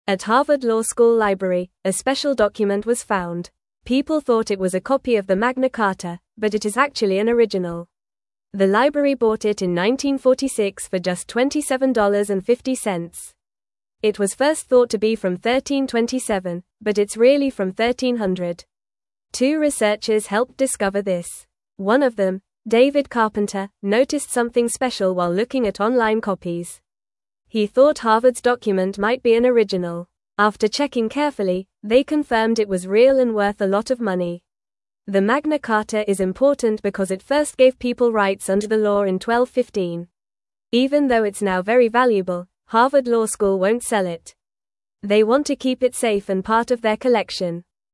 Fast
English-Newsroom-Lower-Intermediate-FAST-Reading-Harvard-Finds-Special-Old-Paper-Called-Magna-Carta.mp3